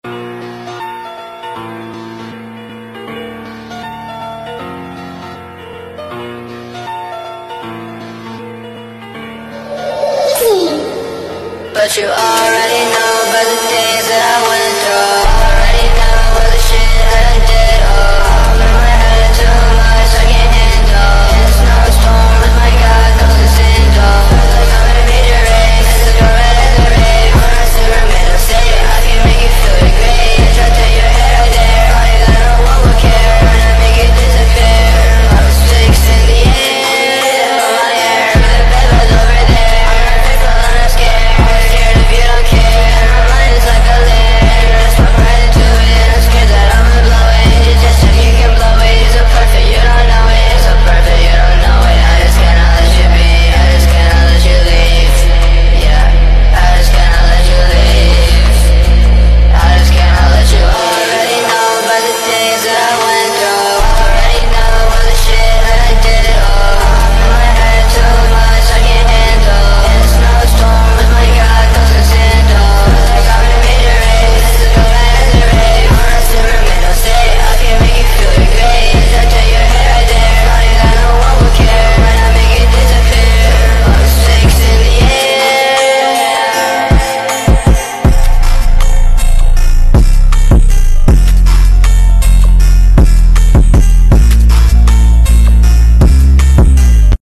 mixing is garbage.